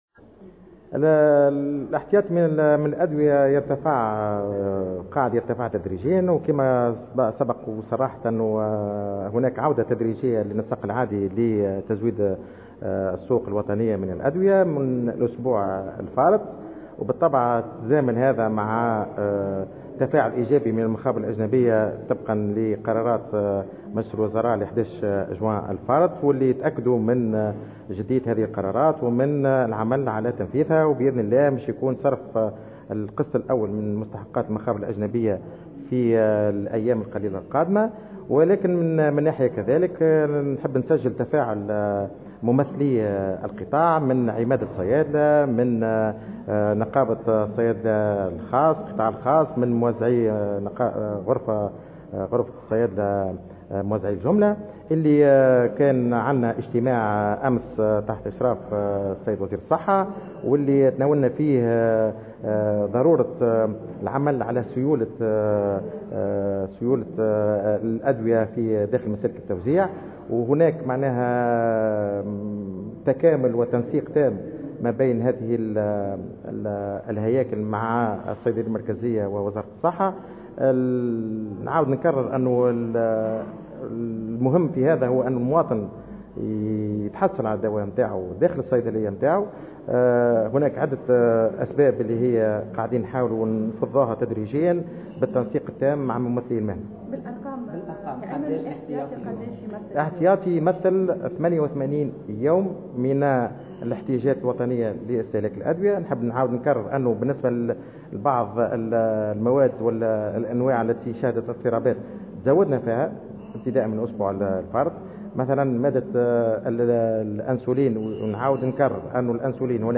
وأوضح المكي في تصريح لمراسلة الجوهرة أف أم، على هامش ملتقى عقد اليوم الأربعاء، حول ازمة الادوية بتونس بمقر المعهد التونسي للدراسات الاستراتيجية، أن احتياطي الأدوية في الصيدلية المركزية بات يغطي 88 من الاحتياجات الوطنية، مشيرا إلى أن احتياطي تونس من مادة الأنسولين يغطي الاستهلاك الوطني طيلة 5 أشهر ونصف، بعد تدارك النقص الحاصل على مستوى نوعين من هذه المادة.